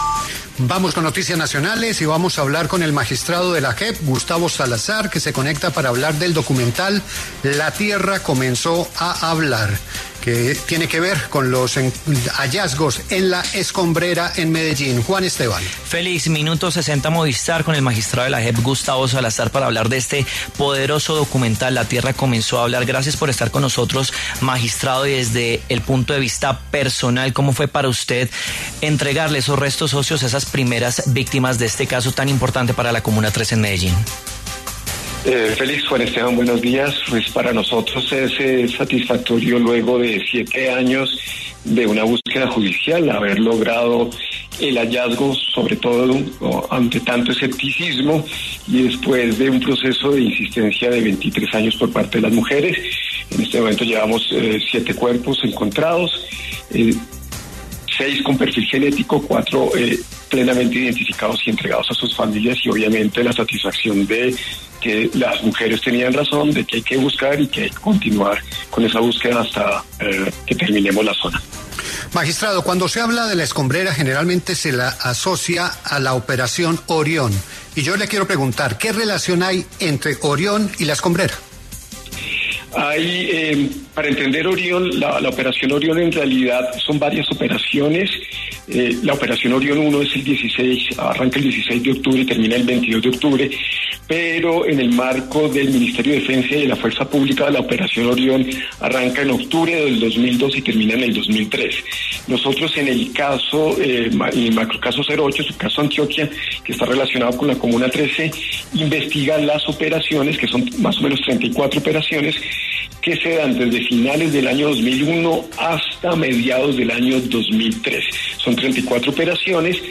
En entrevista con La W Radio, el magistrado de la Jurisdicción Especial para la Paz (JEP), Gustavo Salazar, habló sobre el documental “La tierra comenzó a hablar”, una producción que recoge los hallazgos recientes en la Escombrera de Medellín, sitio clave en la búsqueda de personas desaparecidas durante el conflicto armado y específicamente de la escalada violenta en el segundo semestre del año 2002.